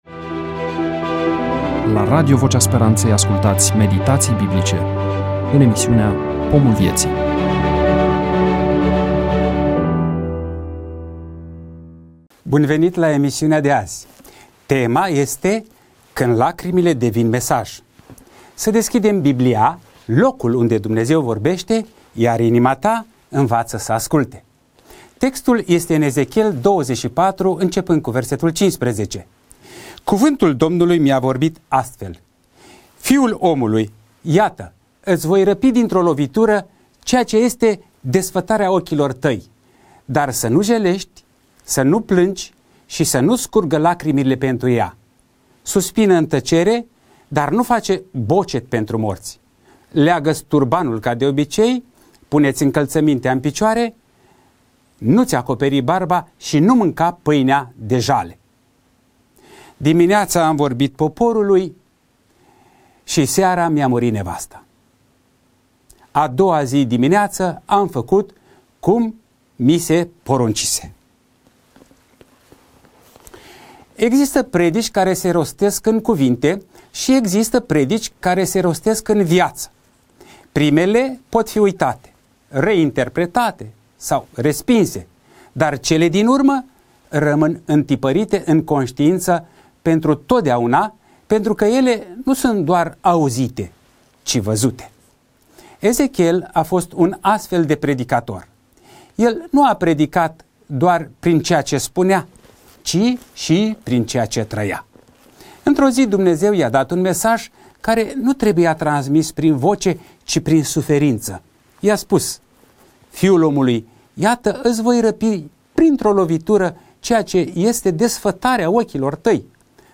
EMISIUNEA: Predică DATA INREGISTRARII: 24.04.2026 VIZUALIZARI: 11